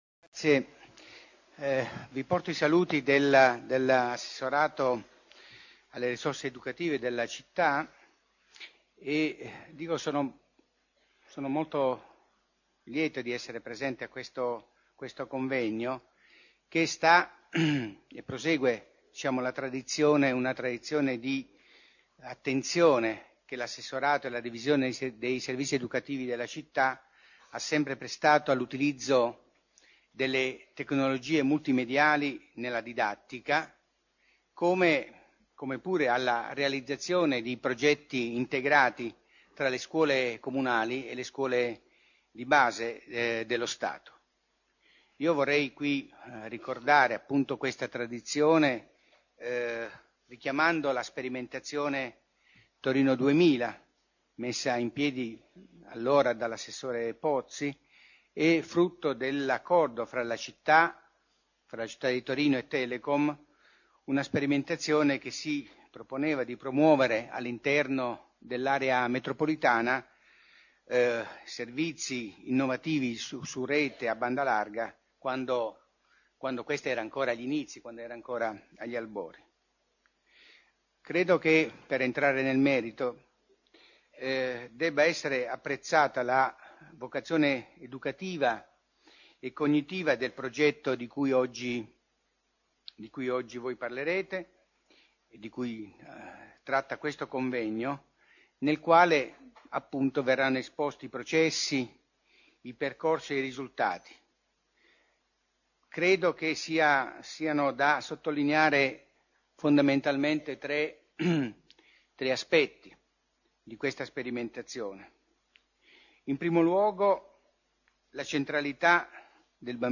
Atti del Convegno del 18 novembre 2006
Saluti delle autorità